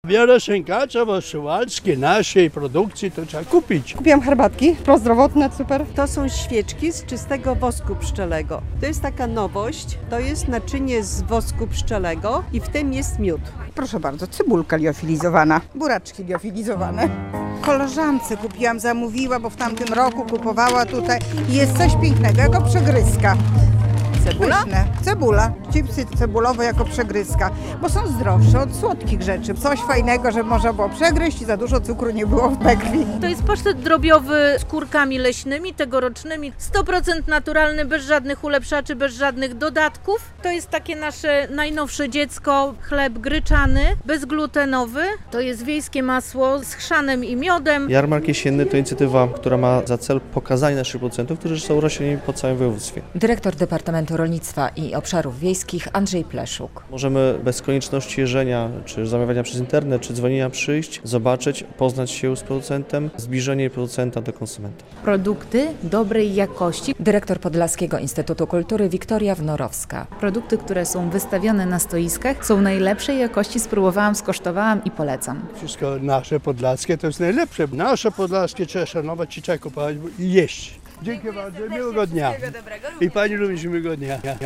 Na około 30 stoiskach są sery, wędliny, przetwory owocowe i warzywne, soki, oleje, wypieki i miody - relacja